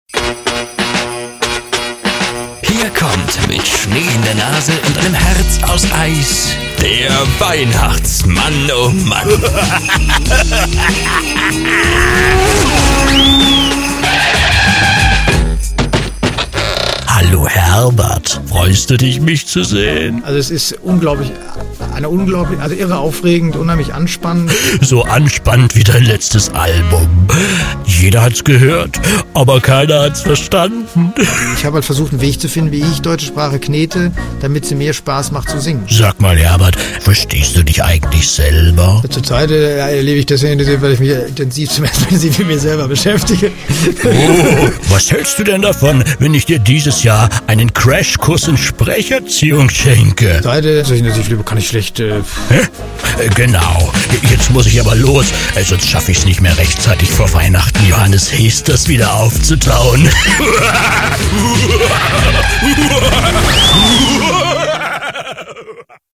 Comedy SR1 / Comedynetwork Weihnachtsmannomann